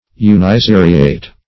Search Result for " uniseriate" : The Collaborative International Dictionary of English v.0.48: Uniseriate \U`ni*se"ri*ate\, a. [Uni- + seriate.] Having one line or series; uniserial.